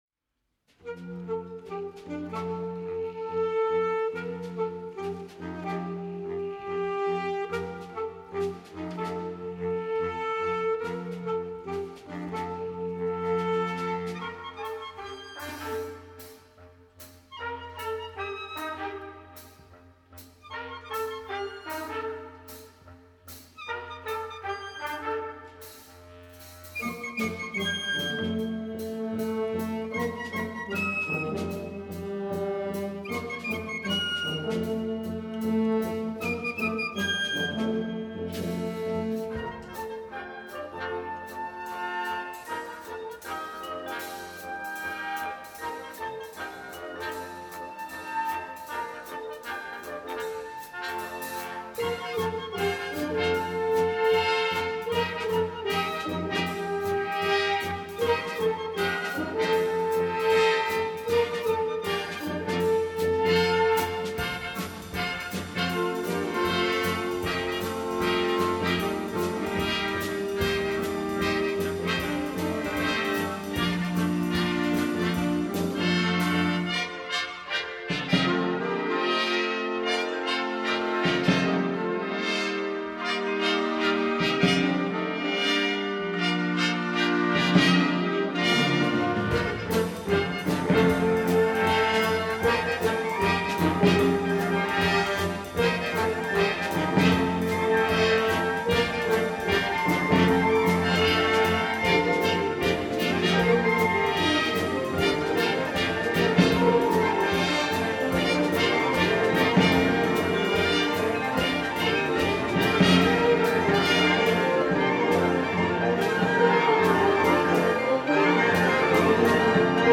Sinfonie